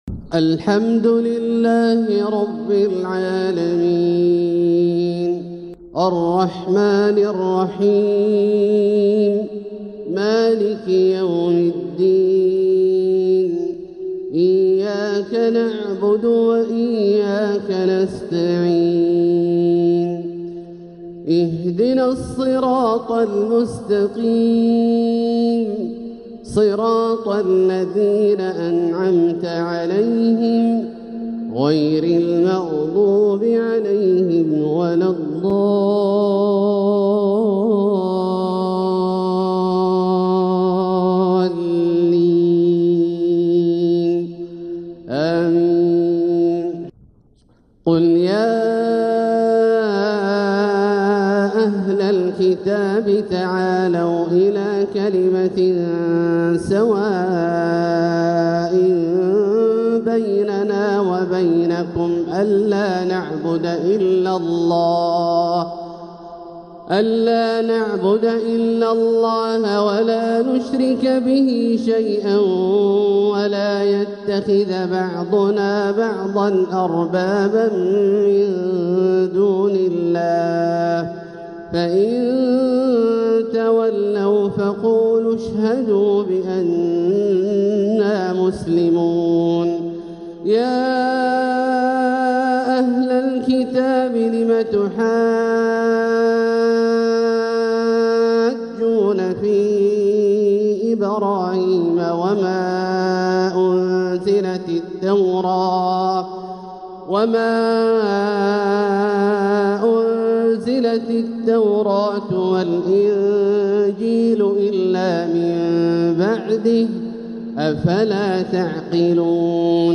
القطف الجني لتلاوات الشيخ عبدالله الجهني | شهر جمادى الآخرة 1446هـ "الحلقة الخامسة والسبعون" > سلسلة القطف الجني لتلاوات الشيخ عبدالله الجهني > الإصدارات الشهرية لتلاوات الحرم المكي 🕋 ( مميز ) > المزيد - تلاوات الحرمين